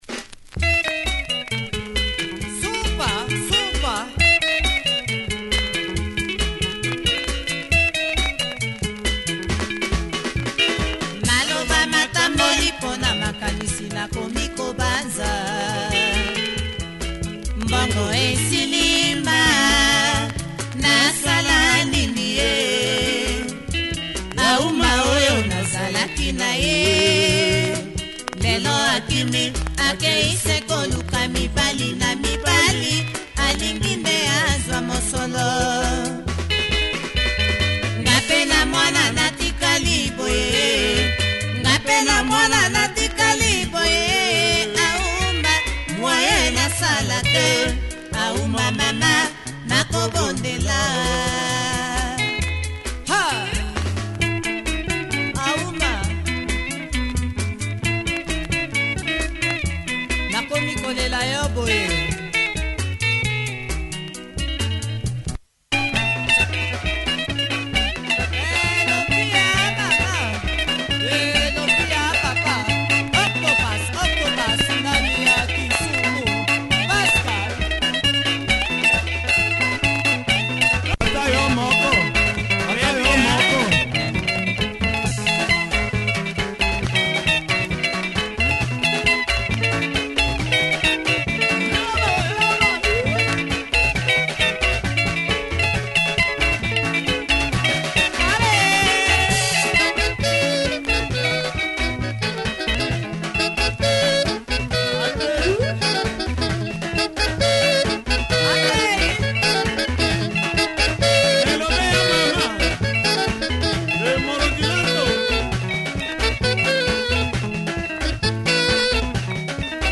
Nice Lingala by this Congo in Kenya group